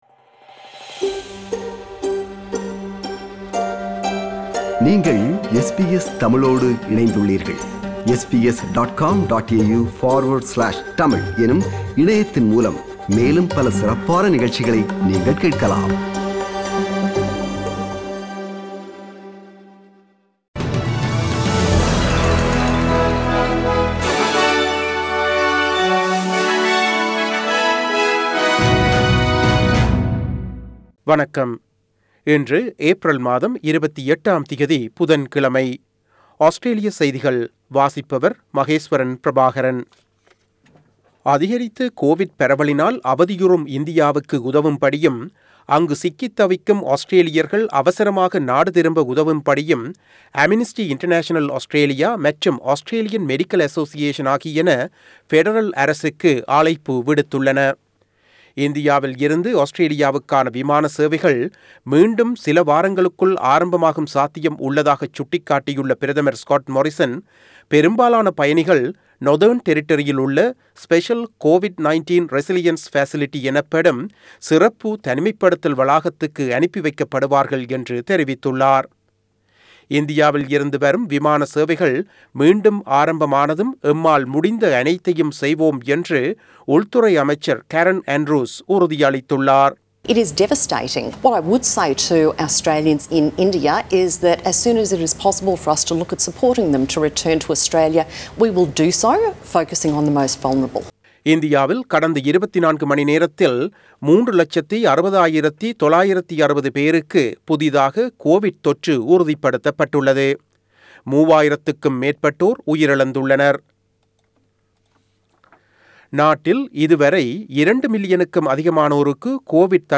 Australian news bulletin for Wednesday 28 April 2021.